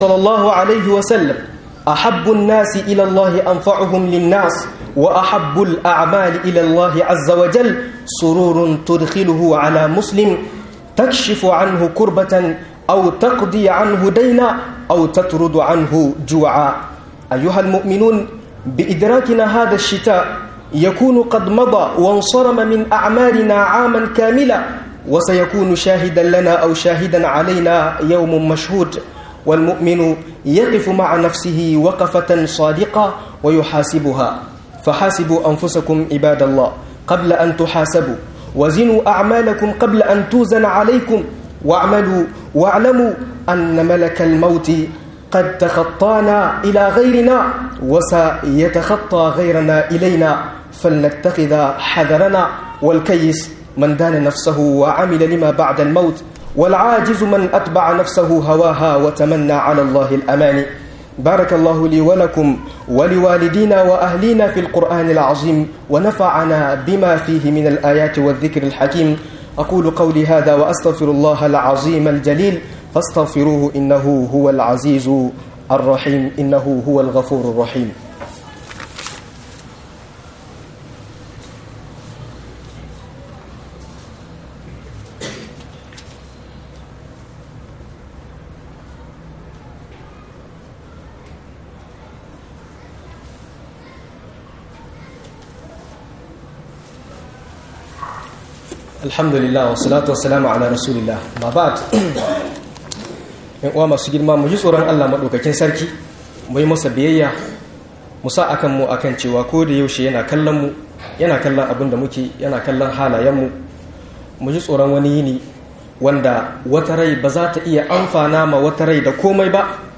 A cikin Sanyi akoy abubuwan lura_Trim - MUHADARA